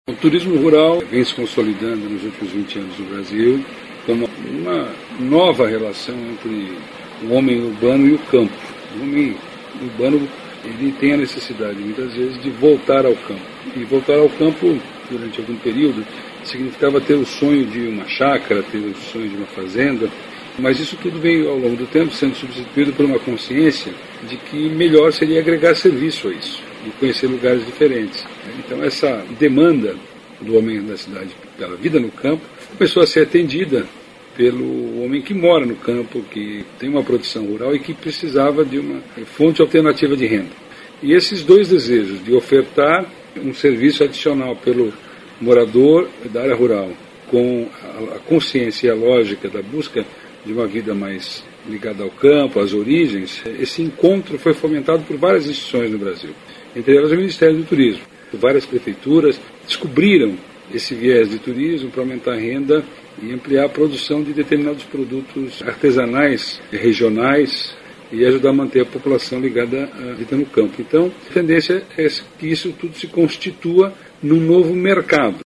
aqui para ouvir declaração do secretário Vinícius Lummertz sobre a importância do turismo rural.